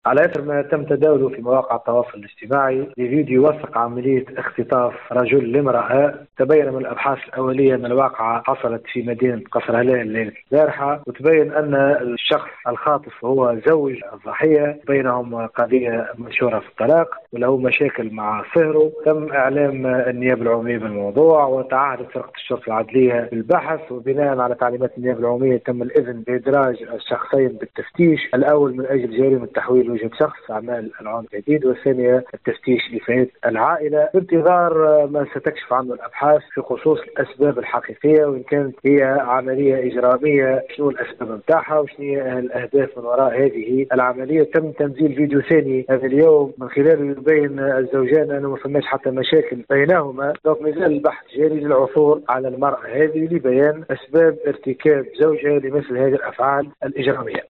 تصريح ل “ام اف ام”